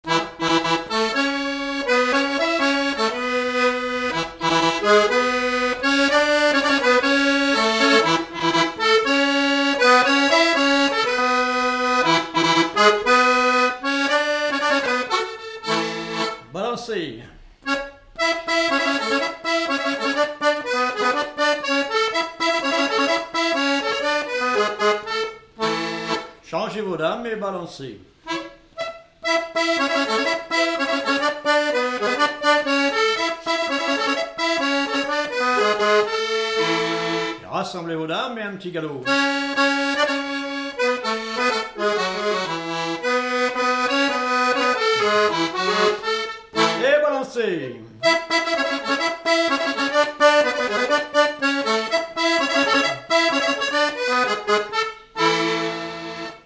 Mémoires et Patrimoines vivants - RaddO est une base de données d'archives iconographiques et sonores.
avant-quatre joué au début et à la fin du quadrille
Pièce musicale inédite